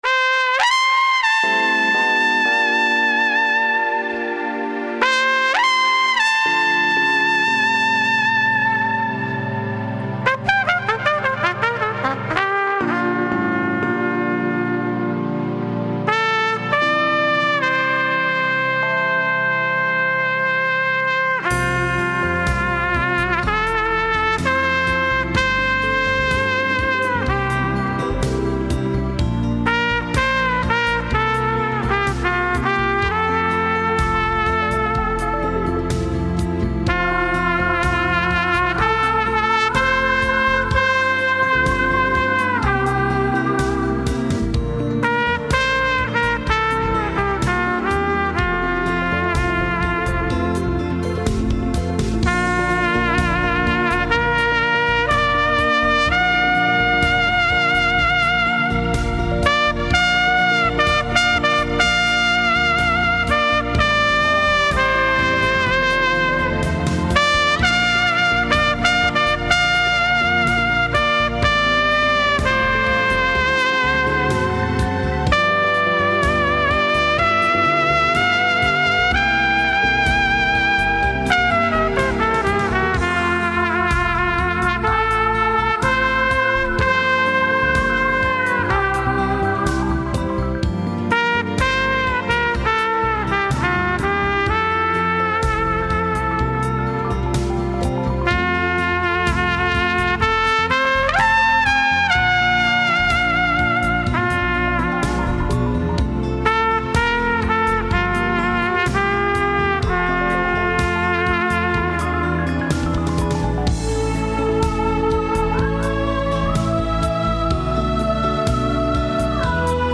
ちなみにオリジナルは後半に転調があるのですが、このバージョンにはありません。
前奏の高い音はB♭のキーで「レ」の音です。